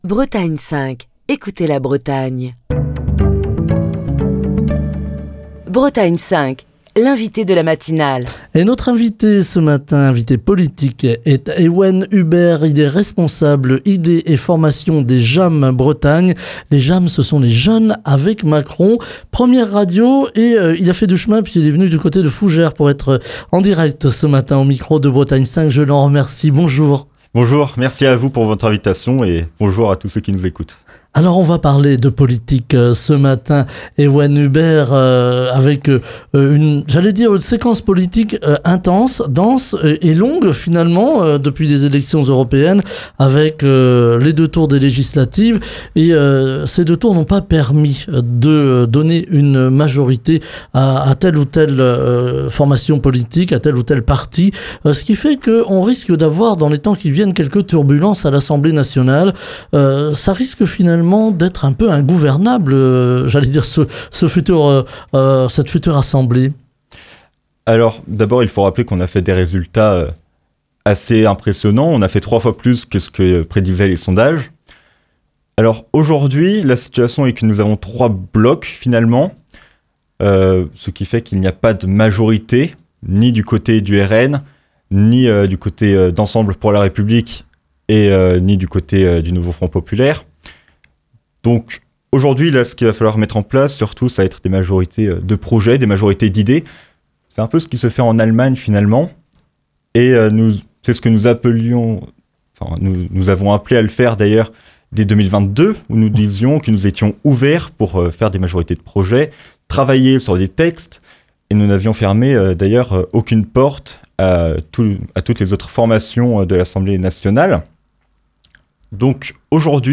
Il souligne que la cohabitation avec les députés du Nouveau Front Populaire s'annonce difficile, notamment pour le vote de lois à caractère social telles que la réforme de l'assurance chômage, ou encore sur des sujets économiques, comme l'augmentation du Smic à 1600 euros. Écouter Télécharger Partager le podcast Facebook Twitter Linkedin Mail L'invité de Bretagne 5 Matin